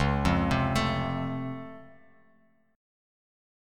C#+M7 chord